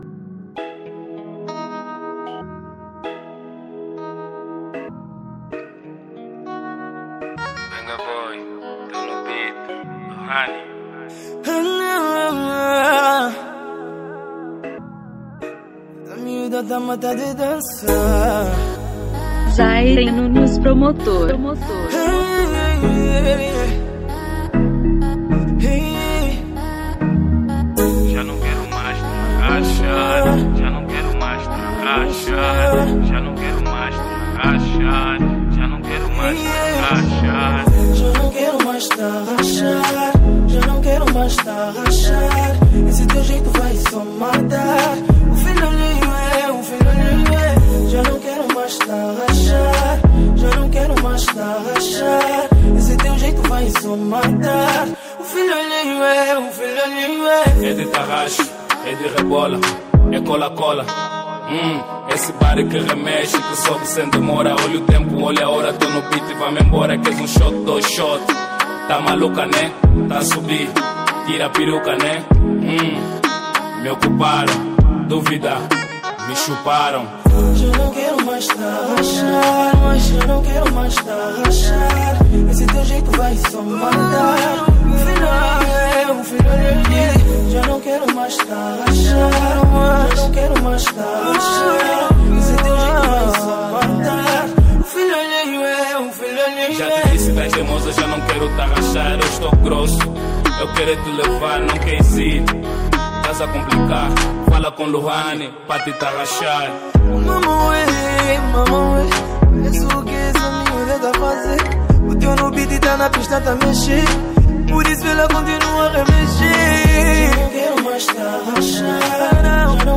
Estilo: Rnb